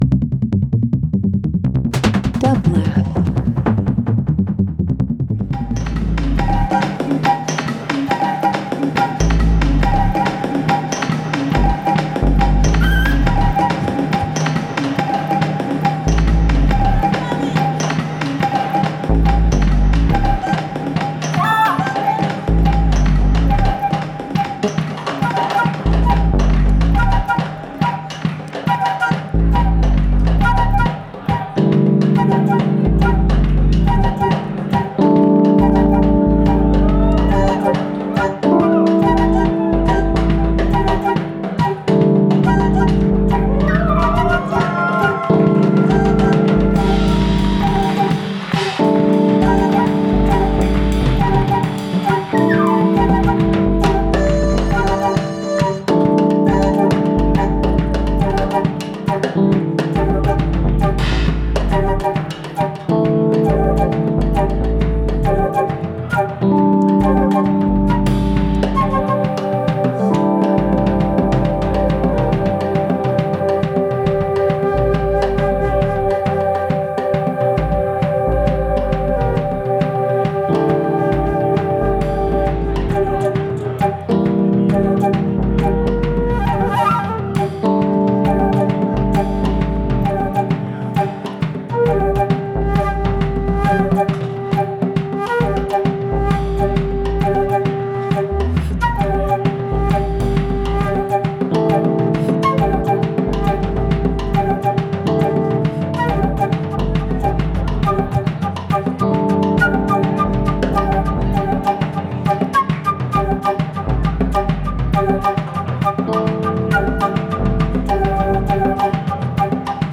LIVE FROM THE LOFT @ PORTER STREET STUDIO – OCT 11, 2025
Experimental Fourth World Jazz Live Performance